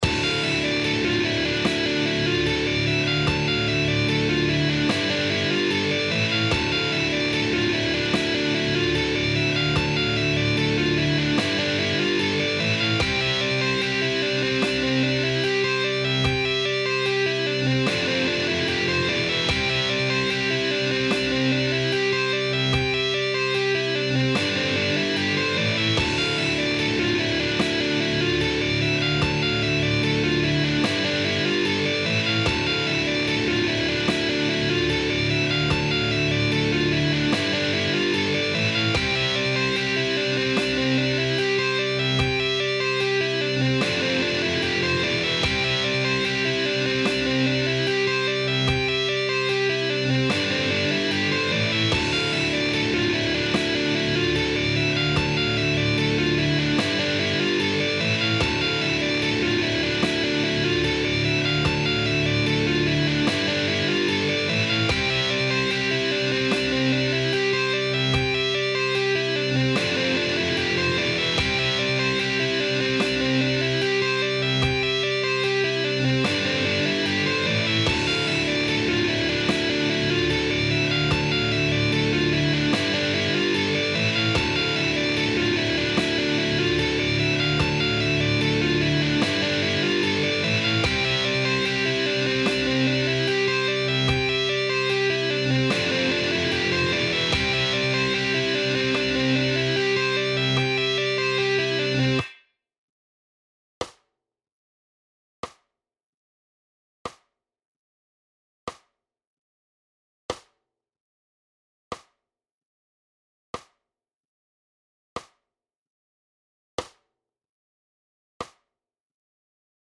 Here listen only the Chorus lick
In 50% Speed: